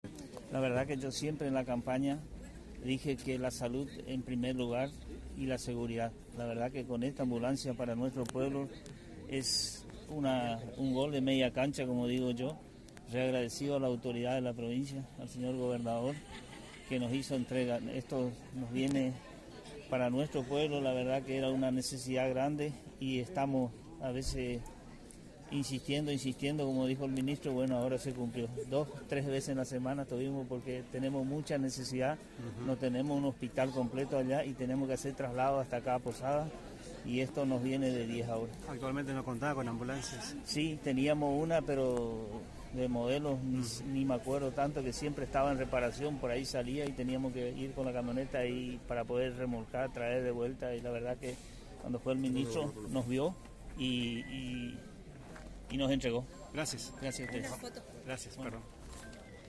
Aquí, el testimonio: